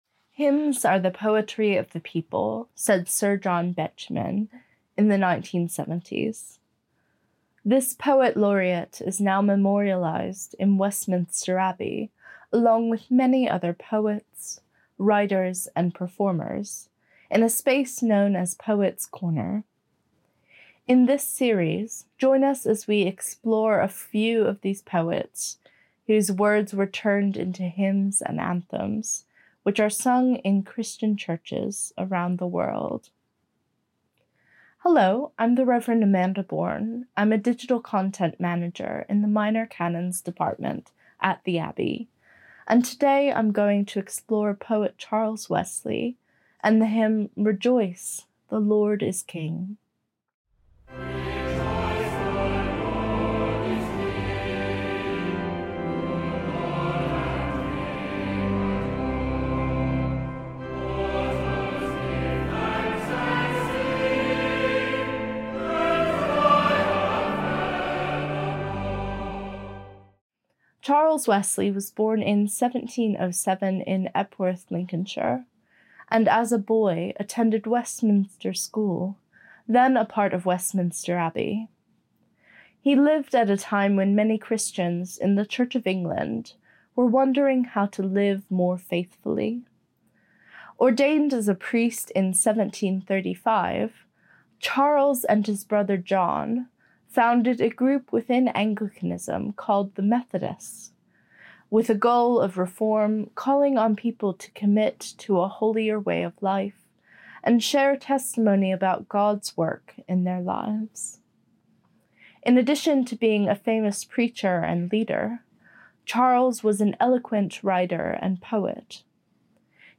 Hear staff from Westminster Abbey as they reflect on the context and the meaning of popular Christian hymns and anthems that came from poetry.